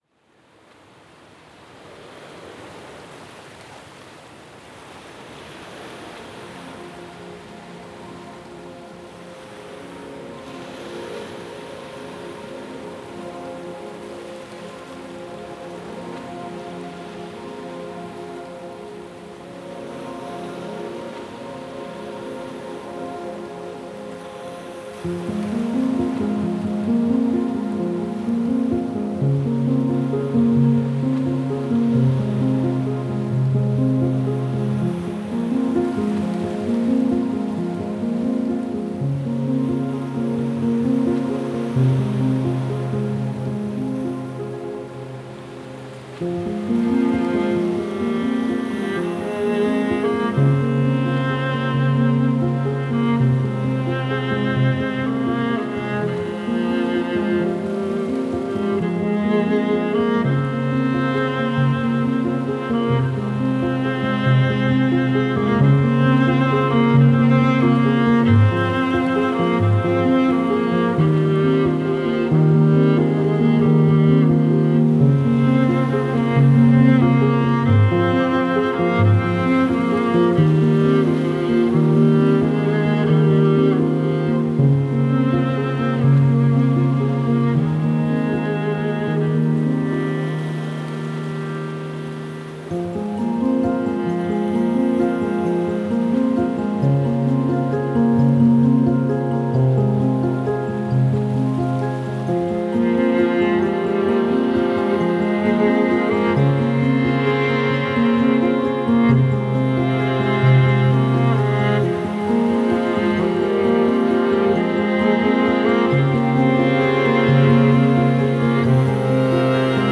emotional and cinematic journey